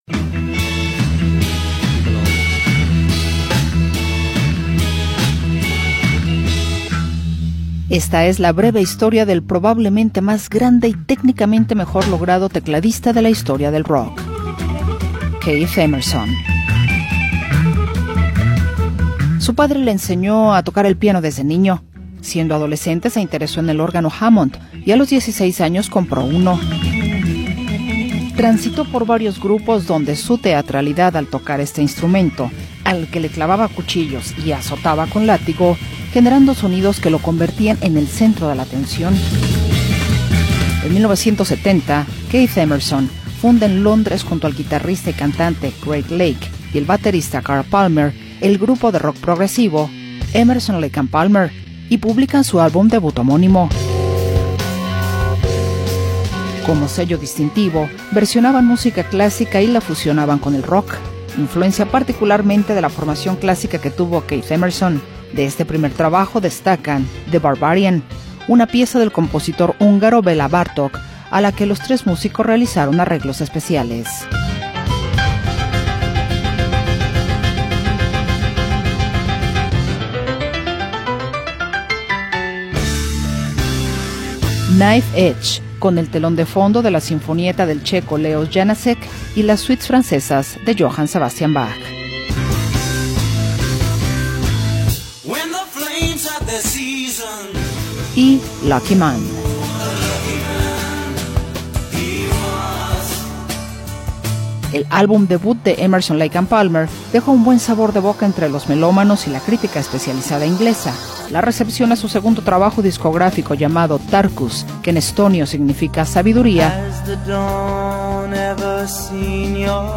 compositor y pionero del rock progresivo.